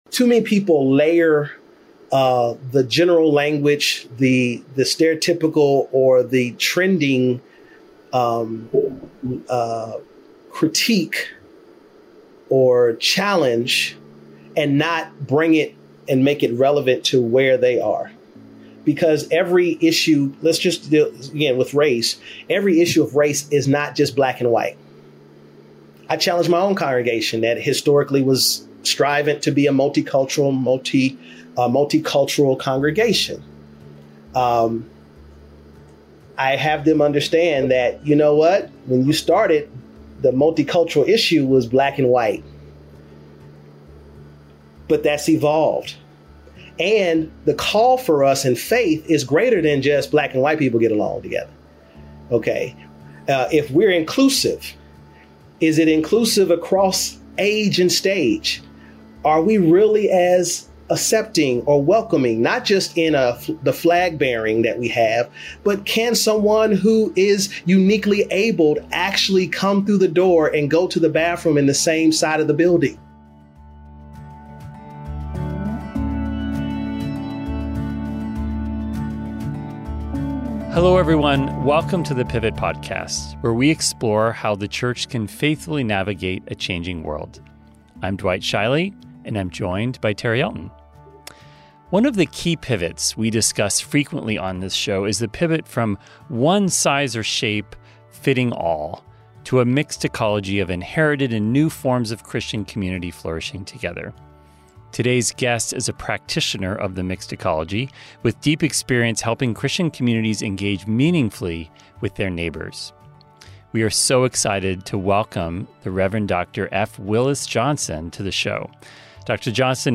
Tune in for a conversation that will inspire you to put your faith into action in meaningful, community-centered ways.